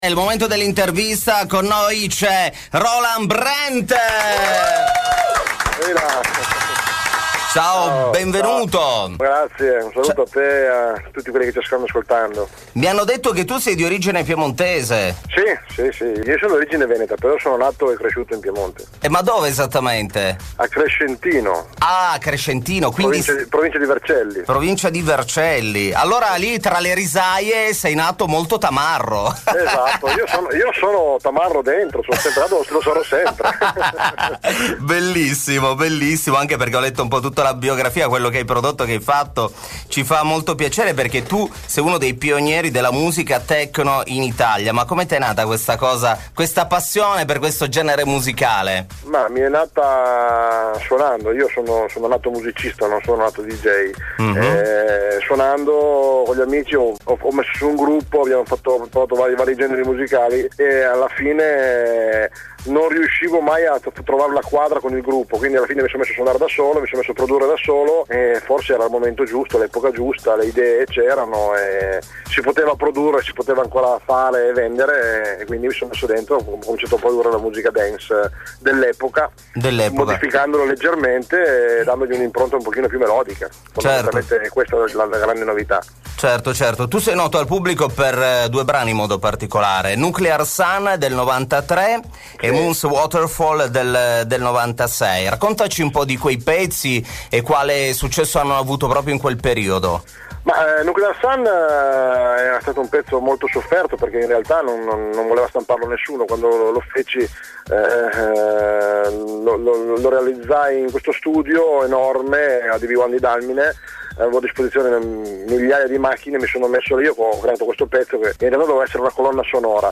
Intervista Whigfield | Dance Attack 2009